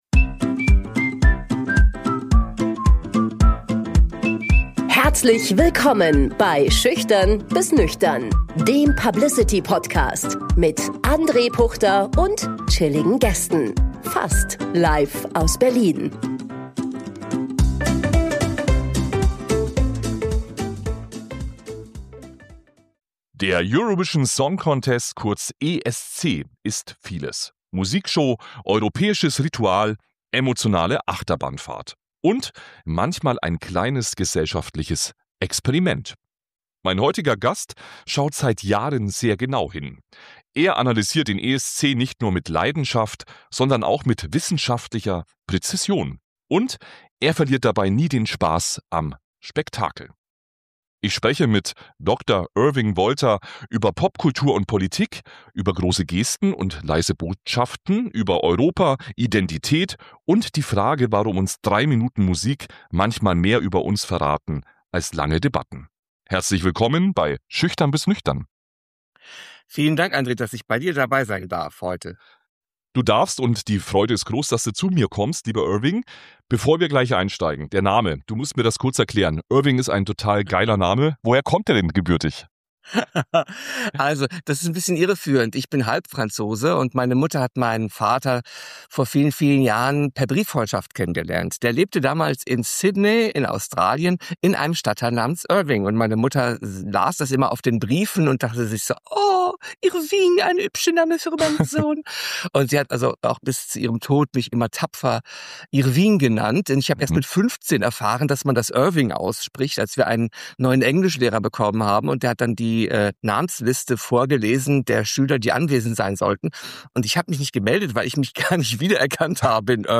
Es geht um Politik und Pop, um große Gesten und leise Botschaften, um Voting-Systeme, Inszenierung und die Frage, warum drei Minuten Musik manchmal mehr über unsere Gesellschaft verraten als lange Debatten. Ein Gespräch für alle, die den größten Musikwettbewerb der Welt lieben, kritisch begleiten – oder ihn nach dieser Folge mit völlig neuen Augen sehen werden.